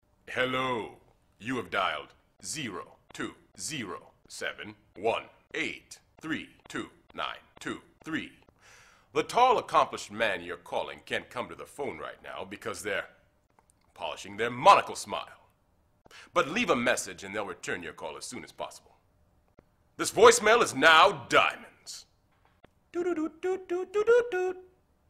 And if proof were needed, other than squillions of video views, that the brand has engaged it's audience fans of news aggregator Reddit have produced the Old Spice Man voicemail generator in 48hrs (listen to Chinwag's effort) by cutting together audio clips from the commercials.
chinwag-voicemail.mp3